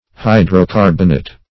Search Result for " hydrocarbonate" : The Collaborative International Dictionary of English v.0.48: Hydrocarbonate \Hy`dro*car"bon*ate\, n. (a) (Old Chem.) A hydrocarbon.
hydrocarbonate.mp3